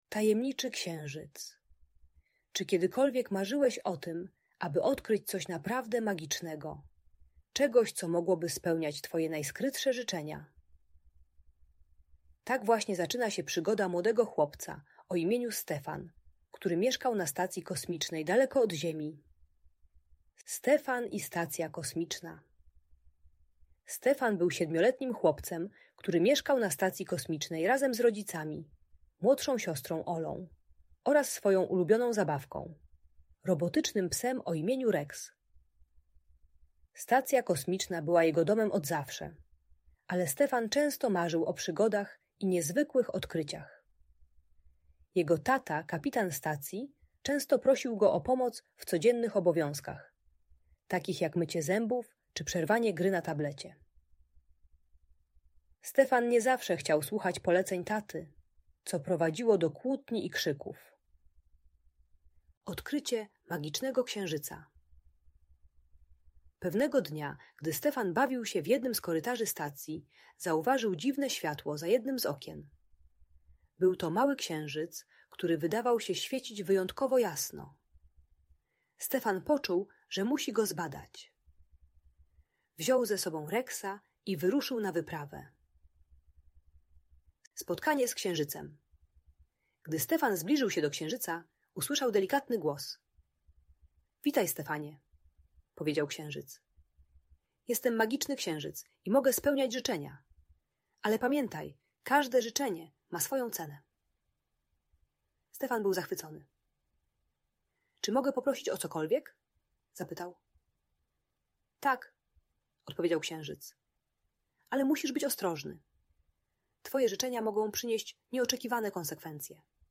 Tajemniczy Księżyc - Audiobajka dla dzieci